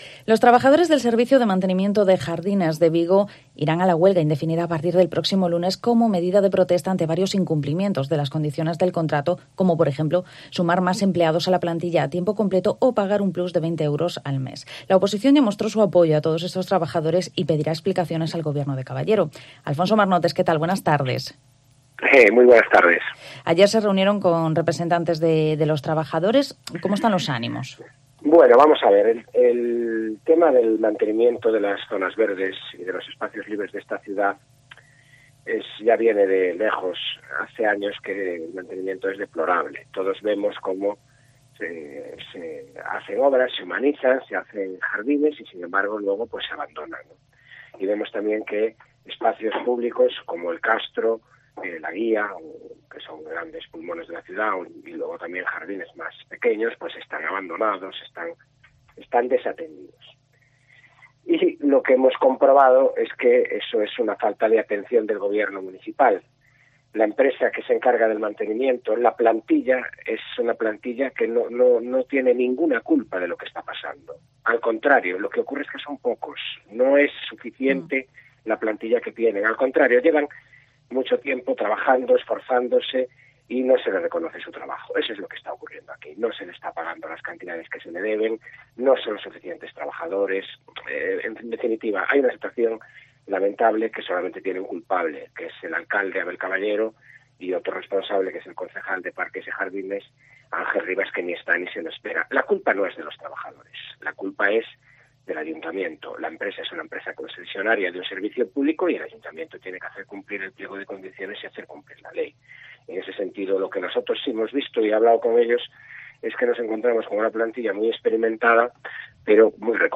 ENTREVISTA
Hablamos con el Portavoz del PP en el Concello de Vigo, Alfonso Marnotes, tras el anuncio de los trabajadores del servicio de Jardines de una huelga indefinida a partir del próximo lunes 19 de abril. También hemos hablado de la reciente condena al ex alcalde pedáneo de Bembrive a 26 meses de cárcel por lucrarse con una obra fantasma.